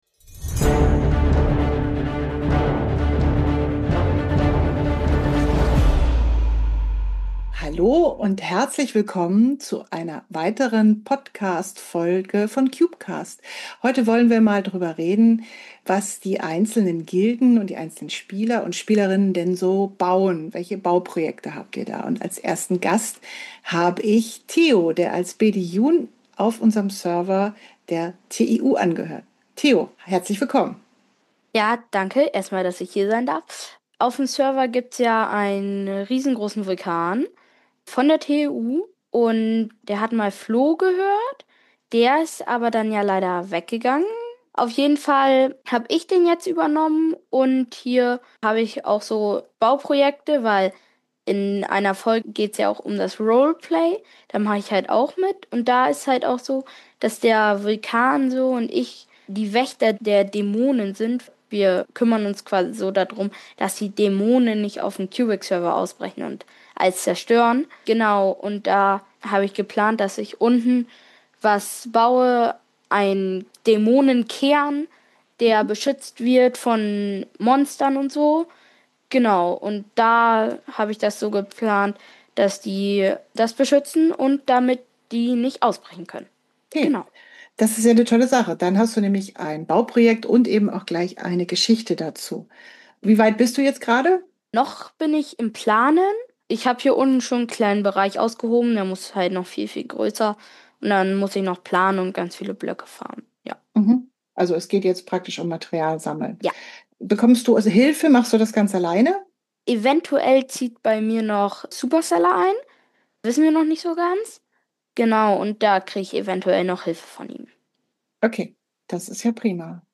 In dieser vierten Folge unseres Minecraft Cubecast erzählen euch drei Spieler von ihren Bauprojekten